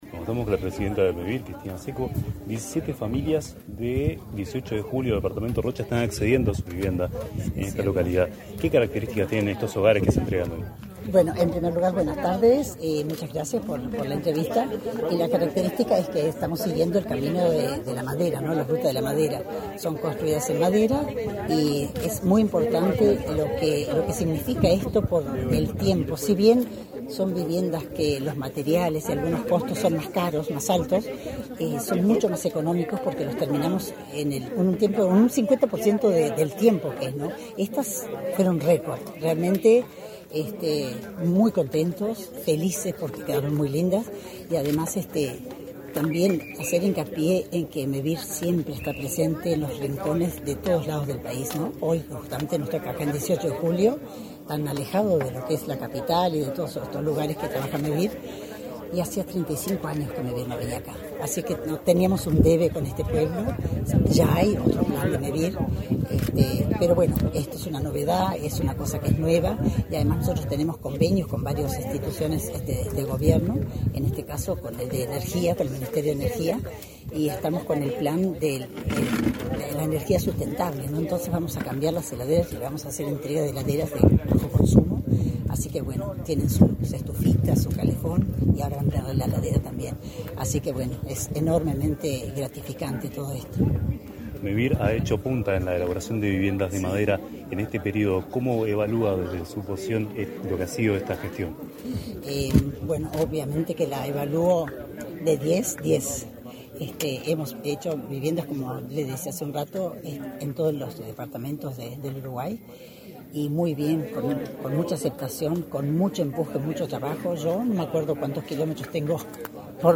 Entrevista a la presidenta de Mevir, Cristina Secco
Entrevista a la presidenta de Mevir, Cristina Secco 24/02/2025 Compartir Facebook X Copiar enlace WhatsApp LinkedIn Mevir inauguró 17 viviendas construidas en madera en la localidad de 18 de Julio, en el departamento de Rocha. En la oportunidad, la presidenta de Mevir, Cristina Secco, realizó declaraciones a Comunicación Presidencial.